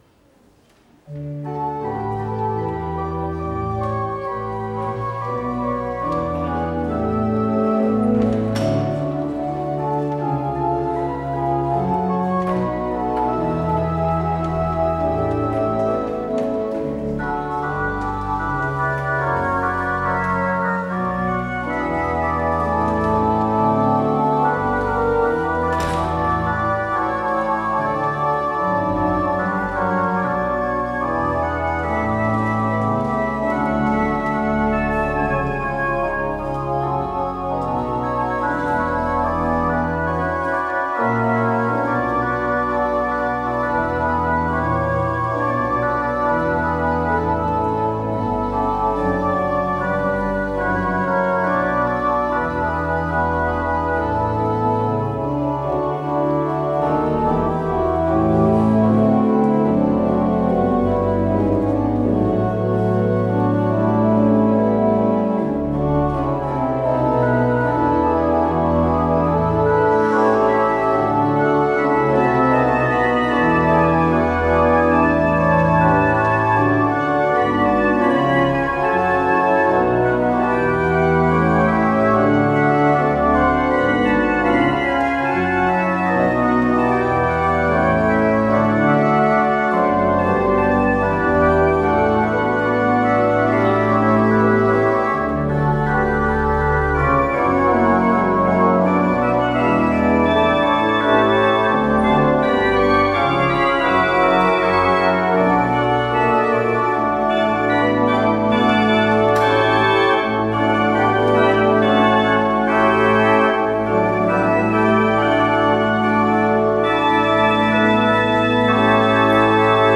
Beluister deze kerkdienst hier: